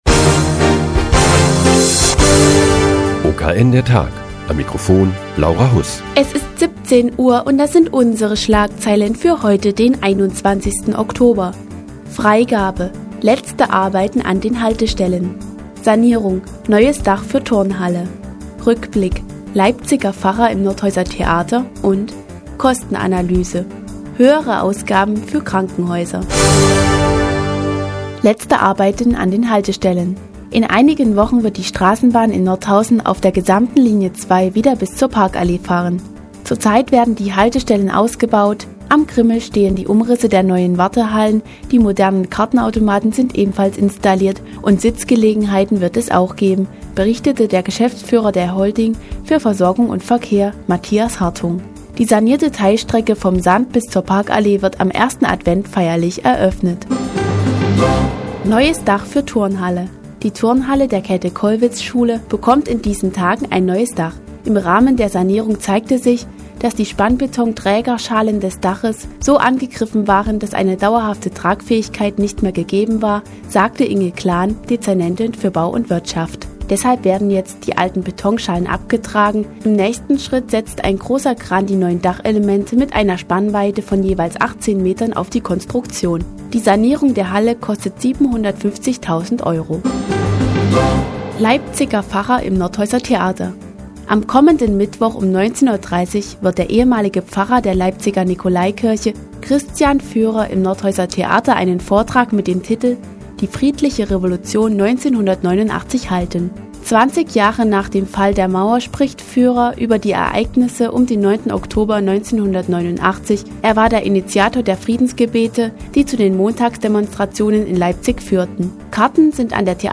Die tägliche Nachrichtensendung des OKN ist nun auch in der nnz zu hören. Heute geht es um ein neues Dach für die Turnhalle der Käthe-Kollwitz-Schule und einen Vortrag über die friedliche Revolution 1989.